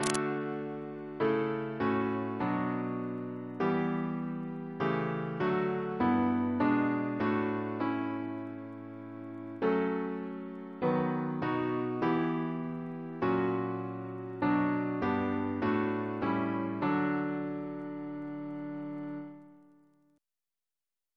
Double chant in D Composer: Samuel S. Wesley (1810-1876), Organist of Hereford and Exeter Cathedrals, Leeds Parish Church, and Winchester and Gloucestor Cathedrals Reference psalters: ACP: 39; H1982: S251